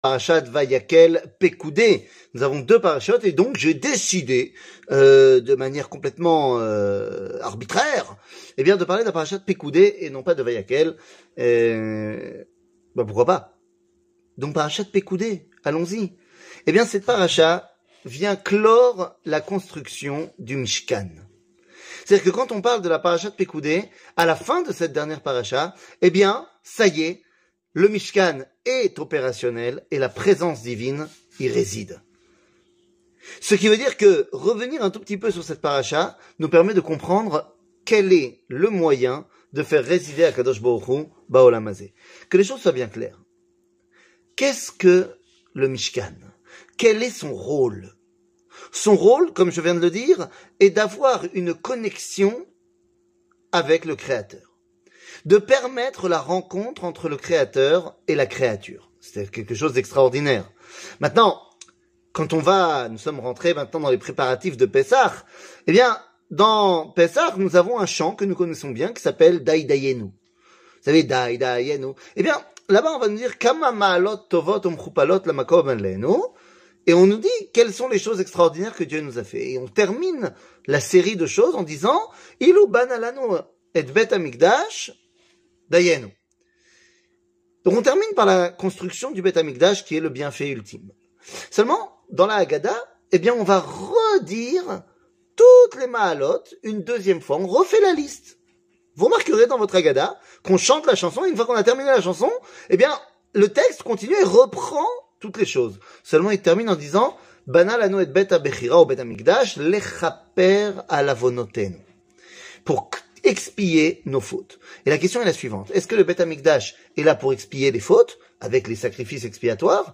Le point Paracha, Pekoudei, Les 18 fois 00:05:55 Le point Paracha, Pekoudei, Les 18 fois שיעור מ 16 מרץ 2023 05MIN הורדה בקובץ אודיו MP3 (5.4 Mo) הורדה בקובץ וידאו MP4 (11.24 Mo) TAGS : שיעורים קצרים